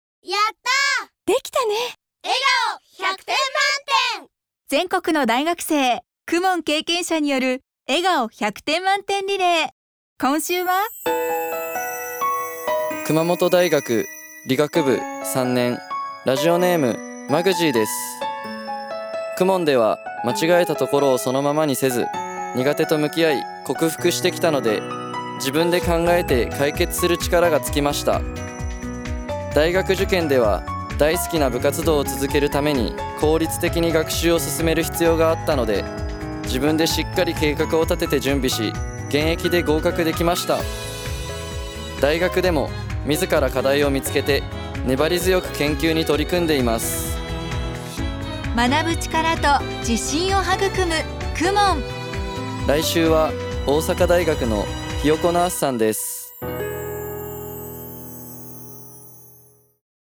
全国の大学生の声